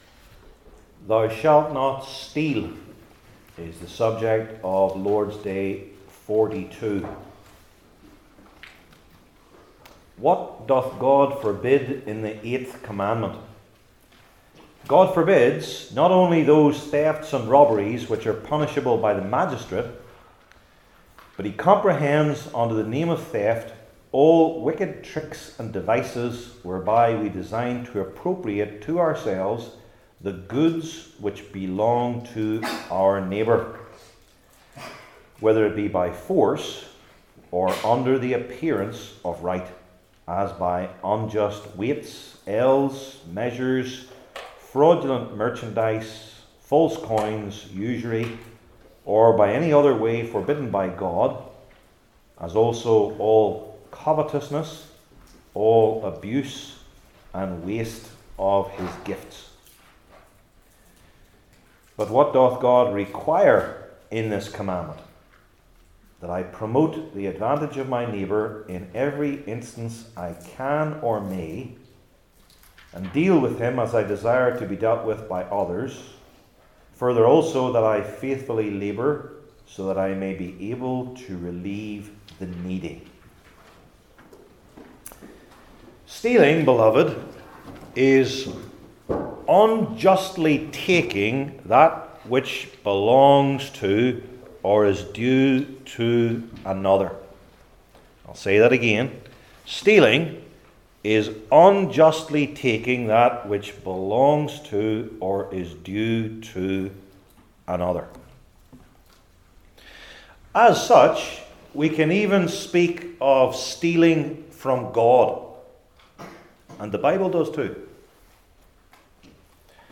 Heidelberg Catechism Sermons I. Some More Obvious Examples II.